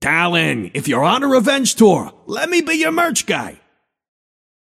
Shopkeeper voice line - Talon, if you’re on a revenge tour, let me be your merch guy.